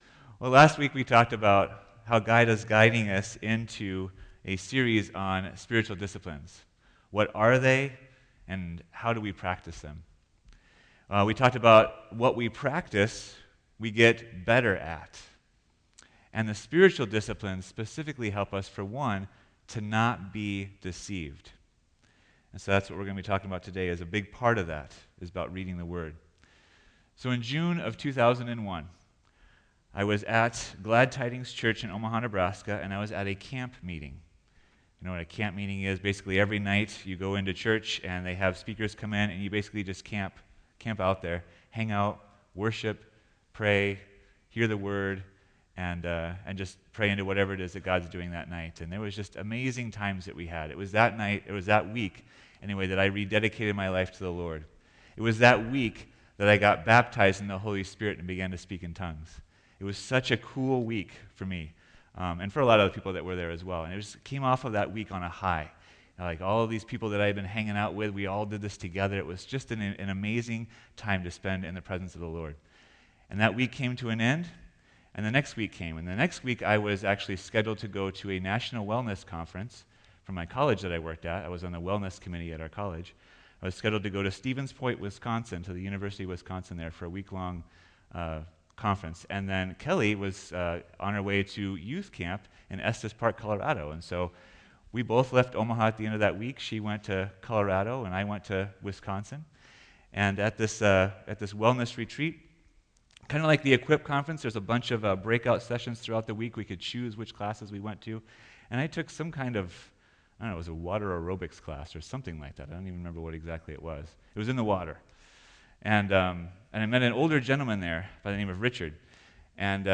Note: This message length is a little longer as there are testimonies at the end.